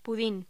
Locución: Puding
voz
Sonidos: Voz humana